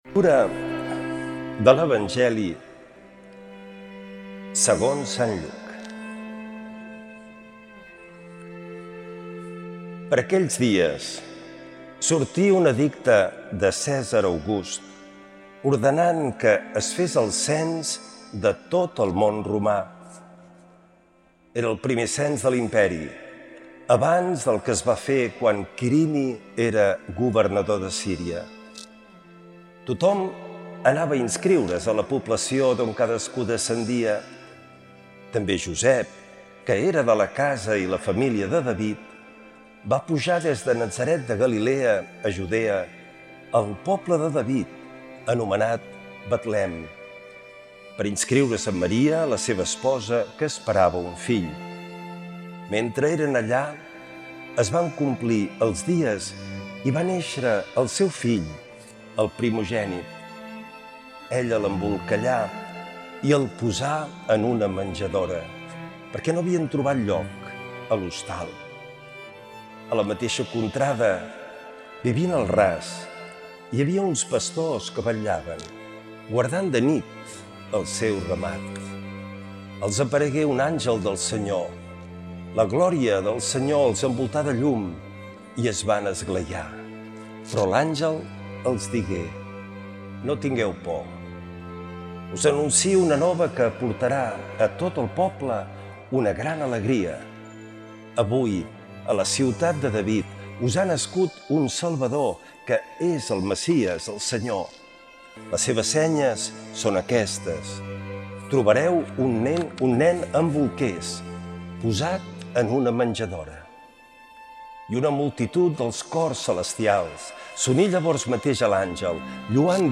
L’Evangeli i el comentari de dijous 25 de desembre del 2025.